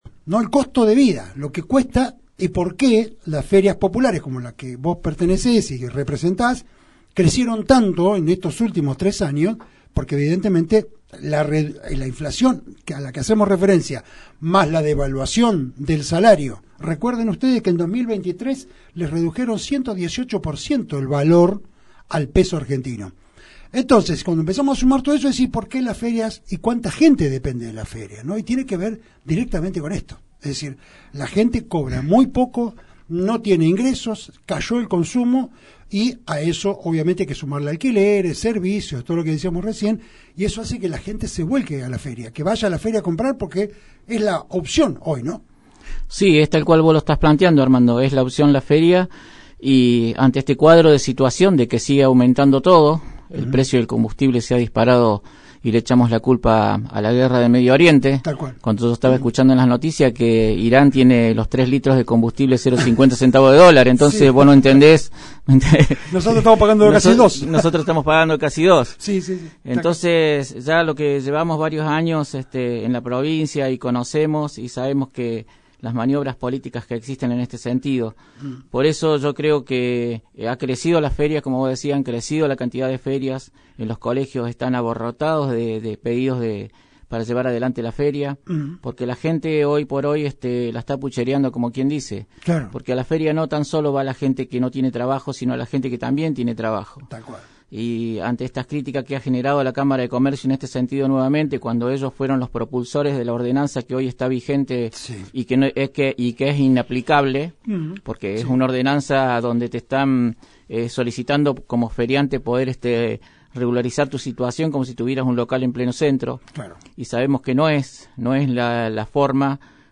En dialogo con Resumen Económico